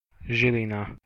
Žilina (Slovak: [ˈʒilina]
Sk-Žilina.ogg.mp3